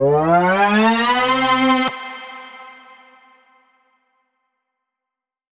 Index of /musicradar/future-rave-samples/Siren-Horn Type Hits/Ramp Up
FR_SirHornC[up]-C.wav